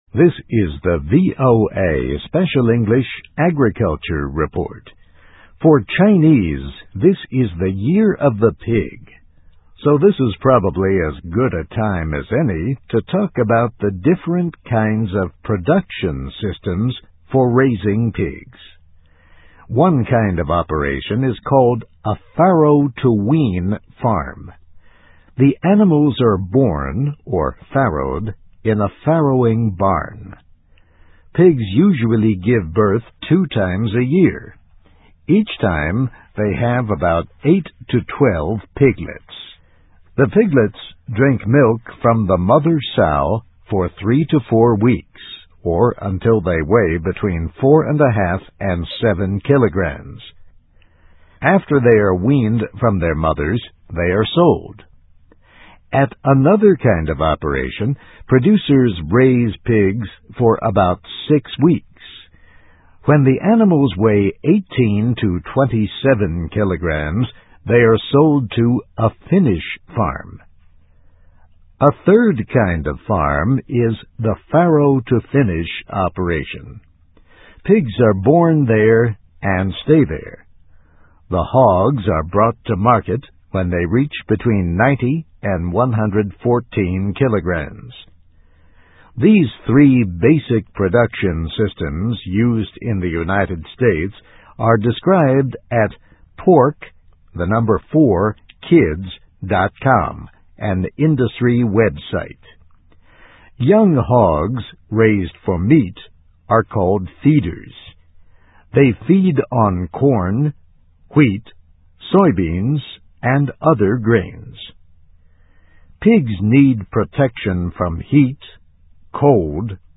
美国之音VOA Special English > Agriculture Report > How This Little Piggy Goes to Market